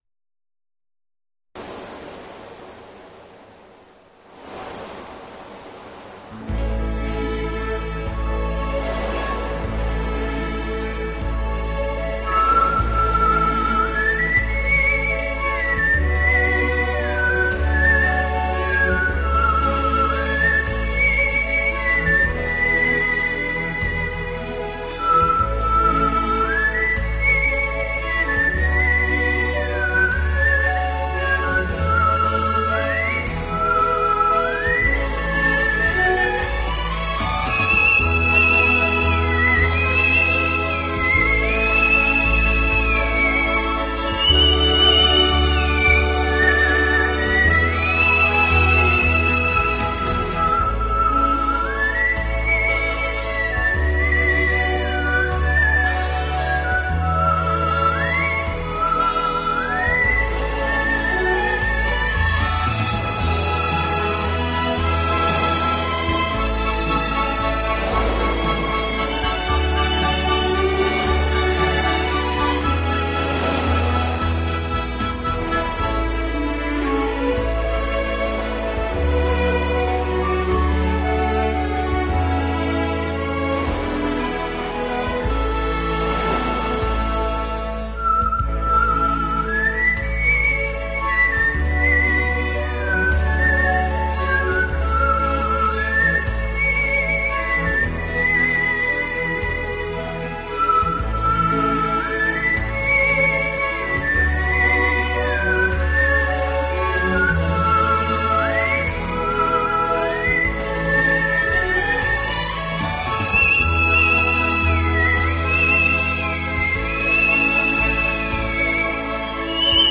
本CD音乐之音源采用当今世界DVD音源制作最高标准：96Khz/24Bit取样录制，其音源所包含
风格独特、别出心裁的口哨音乐，用口唇以高超的技巧吹奏出各种乐曲，使乐曲的情致表现
出令人耳目一新的轻快、柔和、清新、自然、如微风轻轻拨动心弦，在众多音乐形式中显得